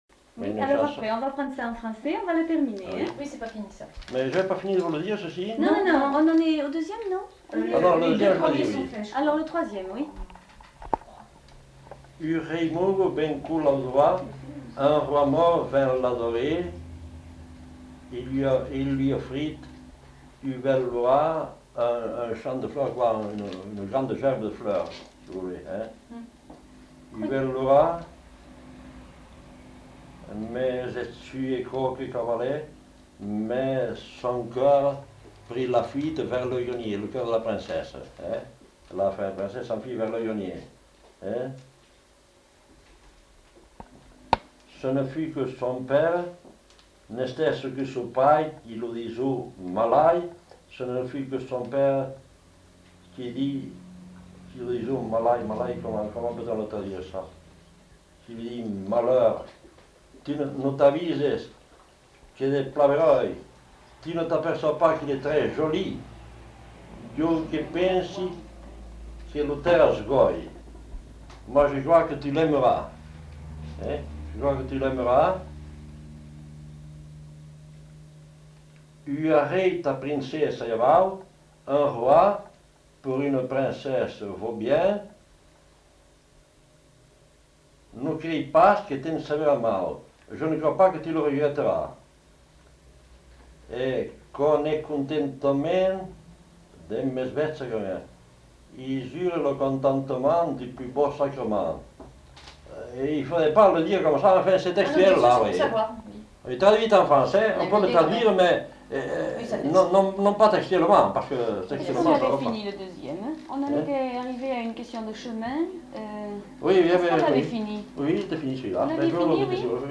Aire culturelle : Bigorre
Genre : parole